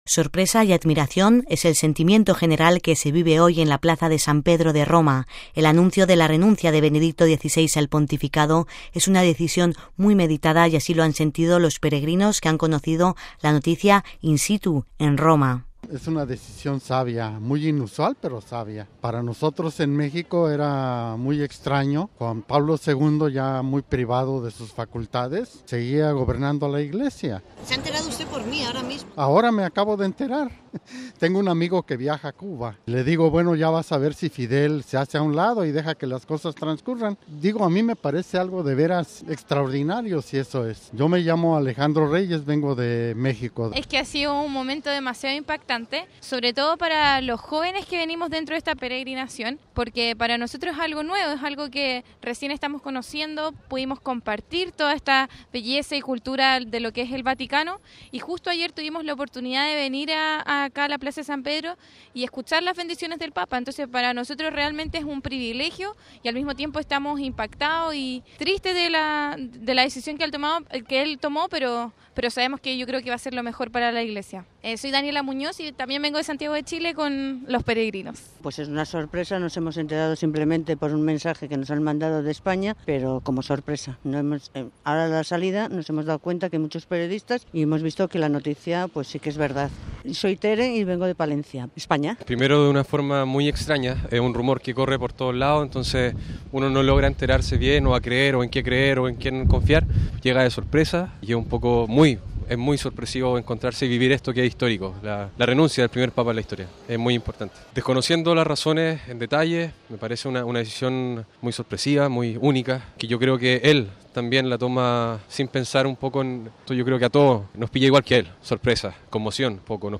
(RV).- (Con audio) Sorpresa y admiración es el sentimiento general que se vive hoy en la plaza de San Pedro de Roma. El anuncio de la renuncia de Benedicto XVI al Pontificado es una decisión muy meditada y así lo han sentido los peregrinos que han conocido la noticia “in situ” en Roma.